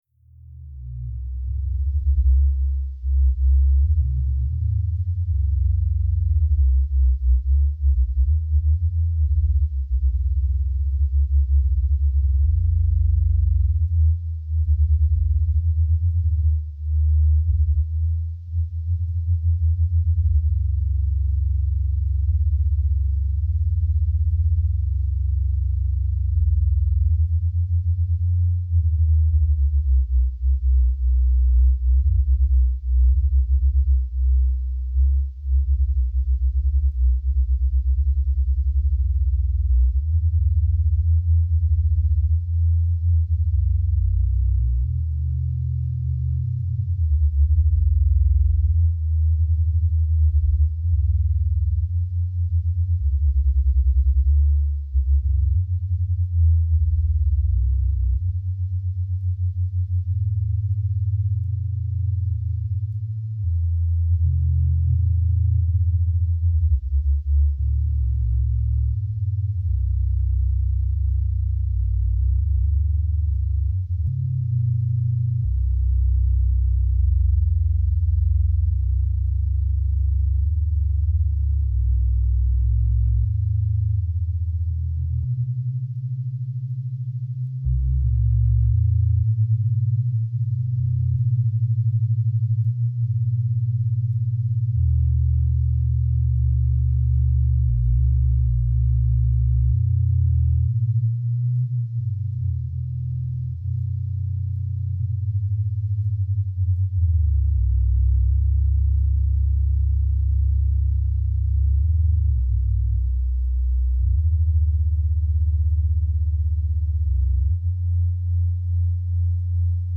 Reasoning around a dark, double walk at night. An hypnotic conversation means sequencing primary necessities, emerging over and over; and exactly when they are calmly reciprocal, they turn out to be a mirrored monologue.
2MenWalkingAndTalking.mp3